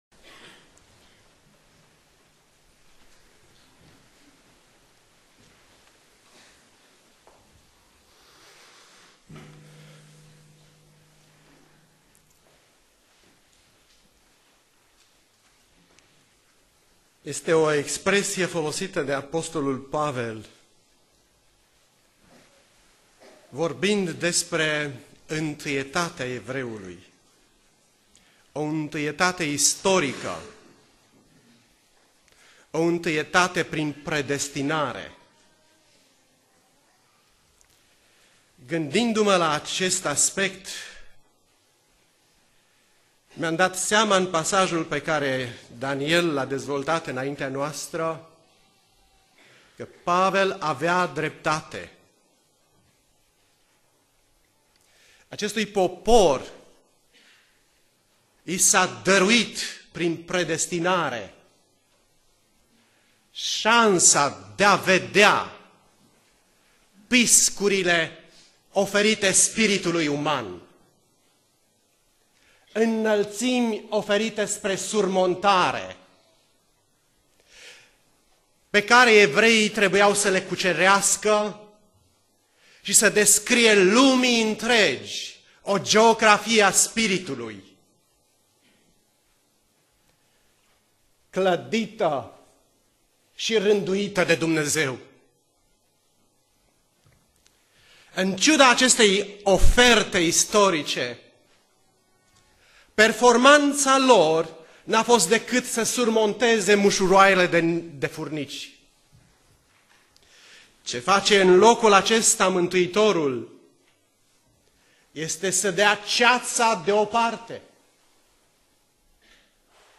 Predica Aplicatie - Matei 5c